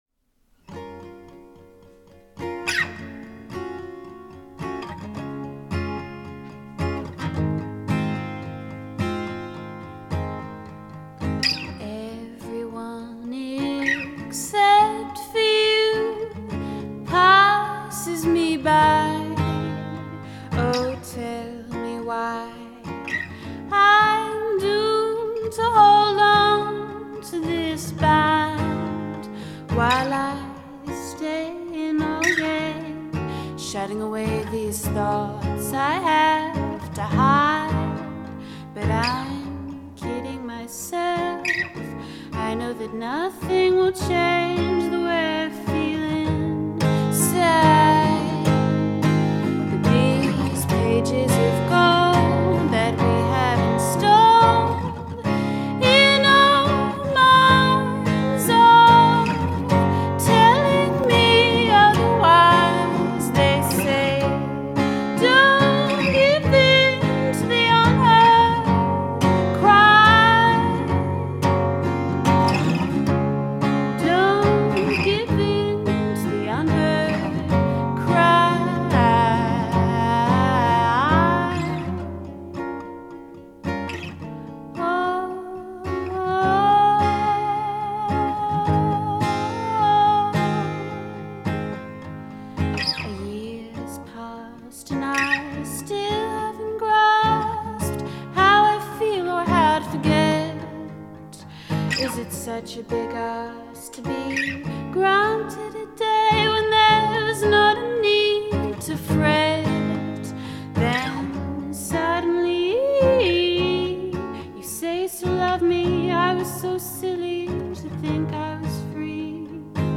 an angelic voice from a far-off life.